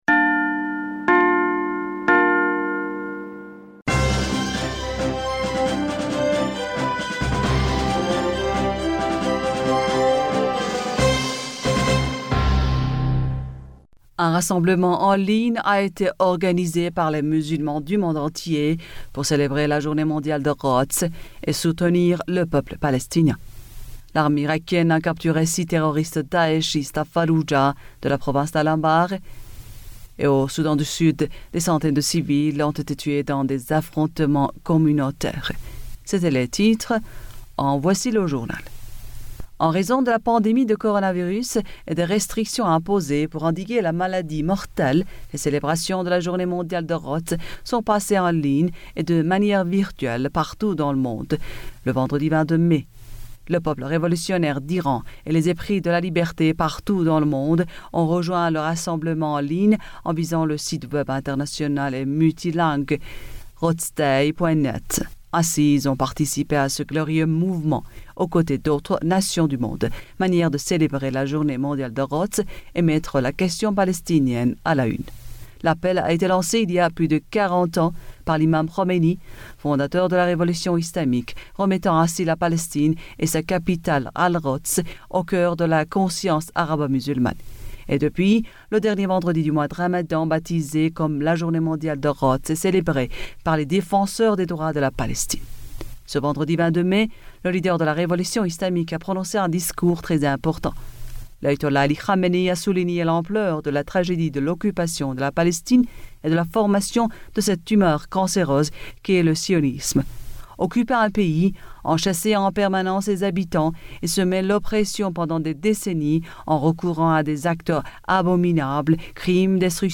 Bulletin d'information du 23 mai 2020